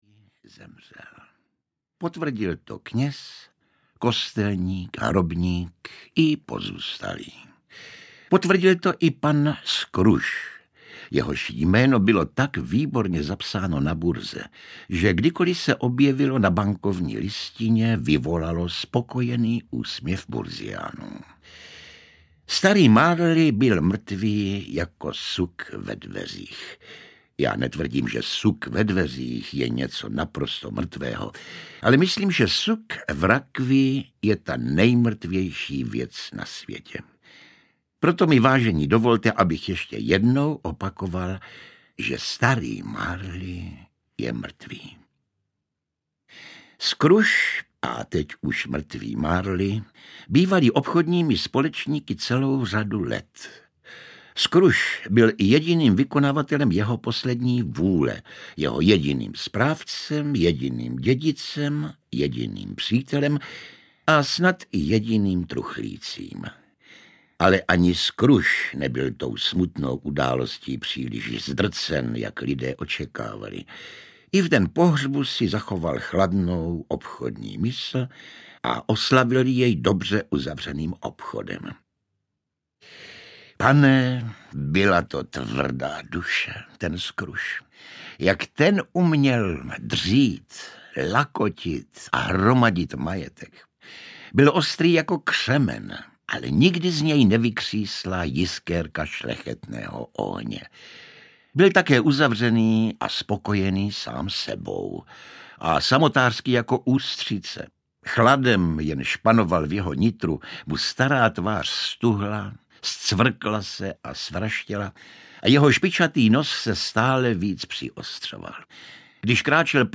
Slavné vánoční příběhy audiokniha
Ukázka z knihy
• InterpretJosef Somr, Ladislav Frej